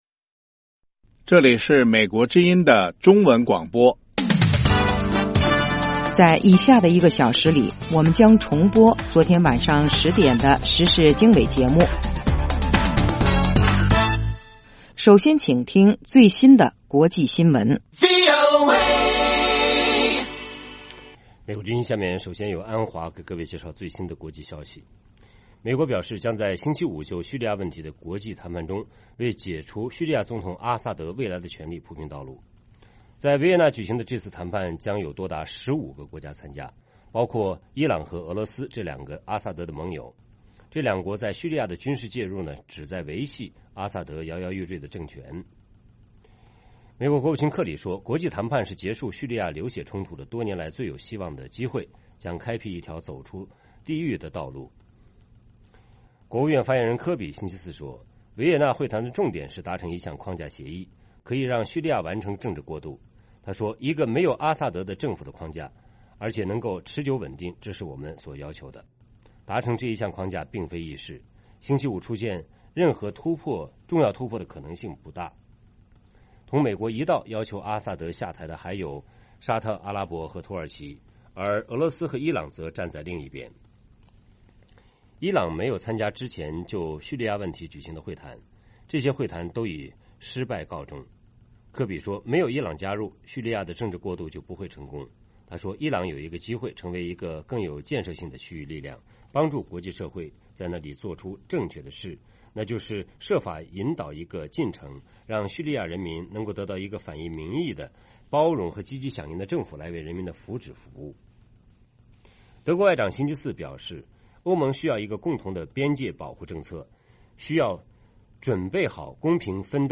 北京时间早上8-9点广播节目 这个小时我们播报最新国际新闻，并重播前一天晚上10-11点的时事经纬节目。